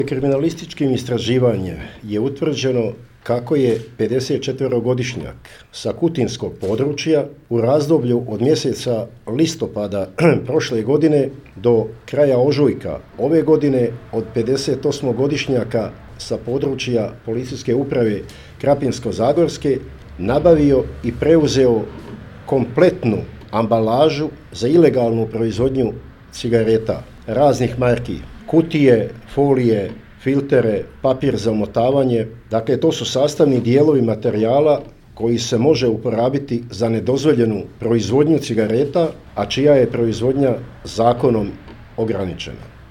O tome je danas na tiskovnoj konferenciji u Sisku izvjestio načelnik PU sisačko-moslavačke Luka Pešut